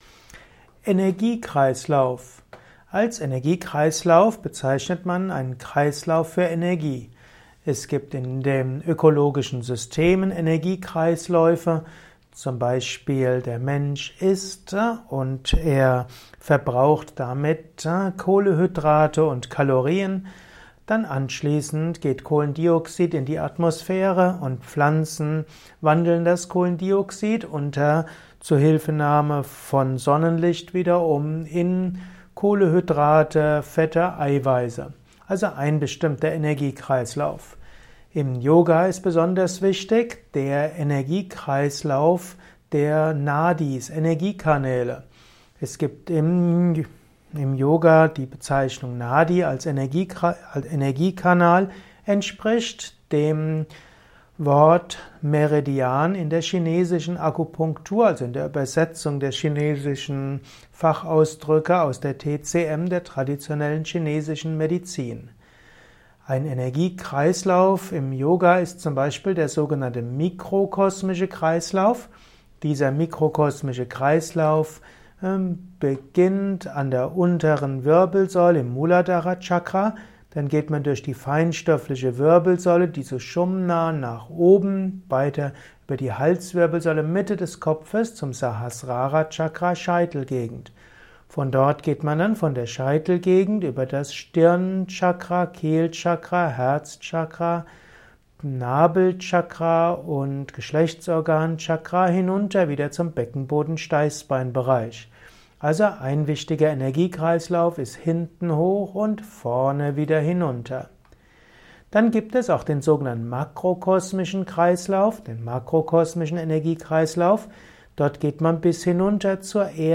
Vortragsaudio rund um das Thema Energiekreislauf. Erfahre einiges zum Thema Energiekreislauf in diesem kurzen Improvisations-Vortrag.